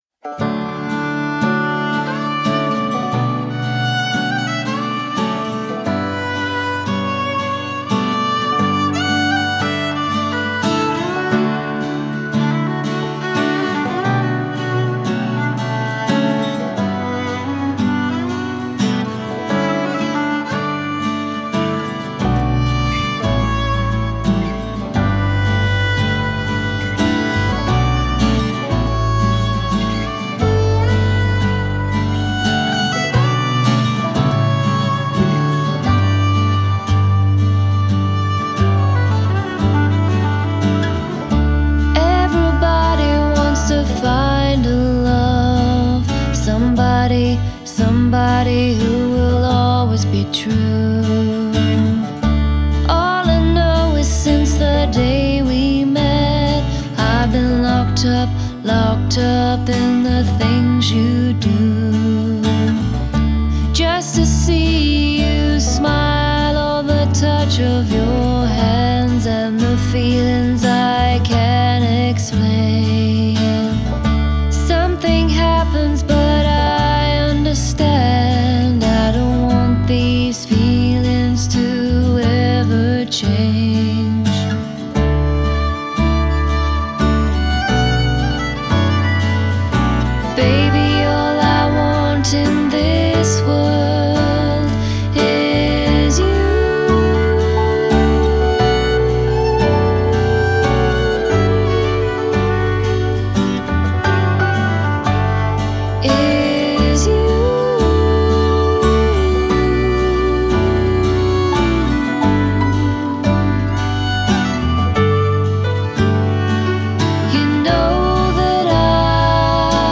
Genre: Folk, Singer-Songwriter